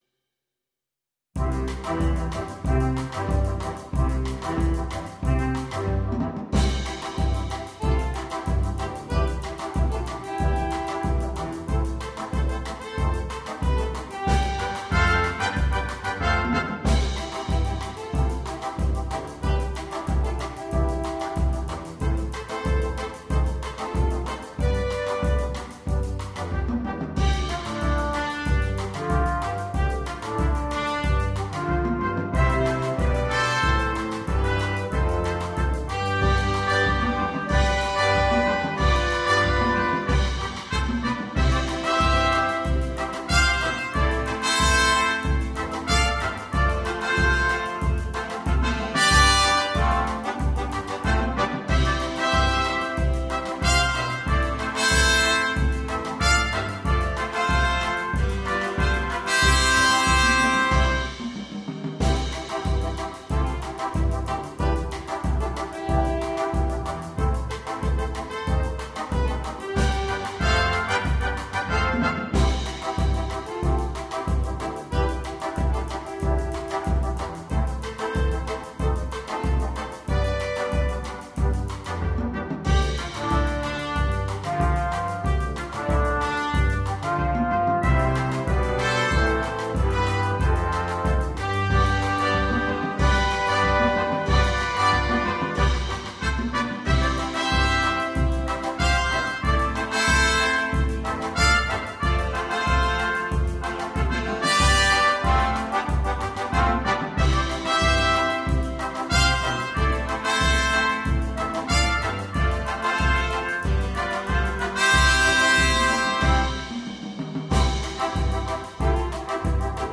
для джаз-бэнда.